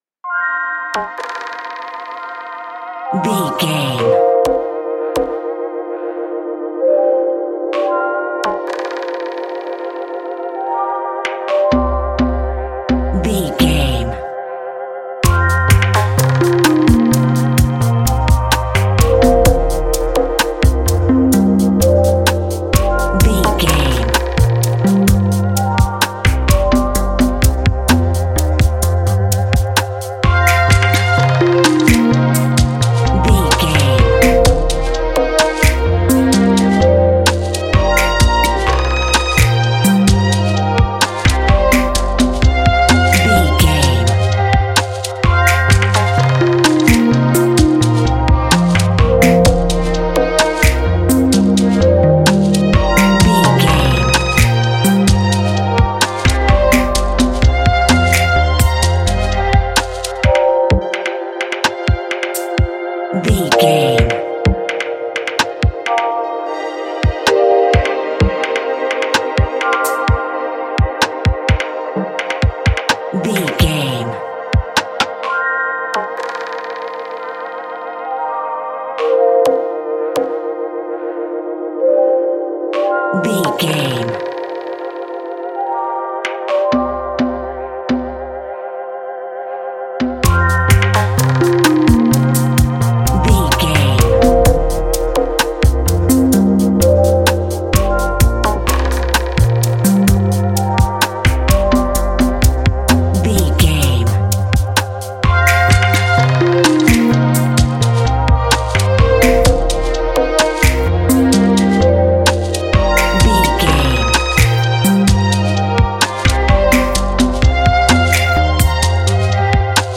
Ionian/Major
violin
ambient
mystical
atmospheric